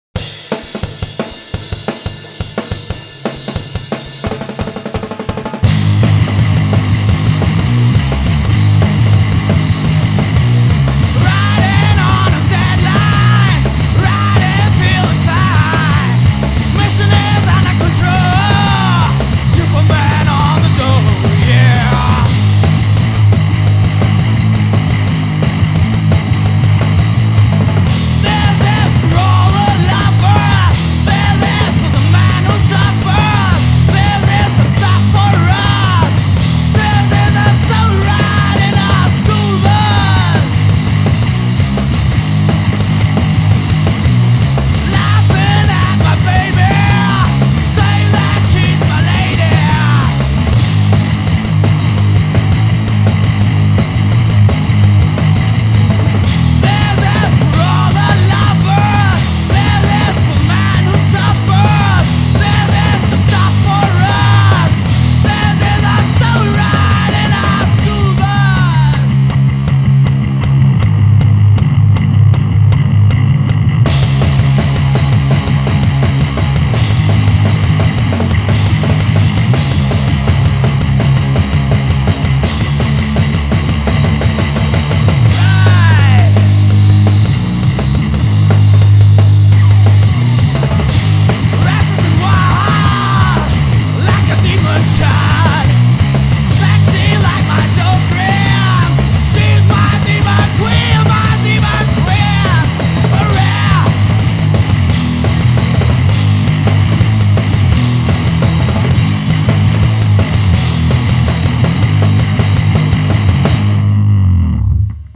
Stoner (Desert Rock), Hard Rock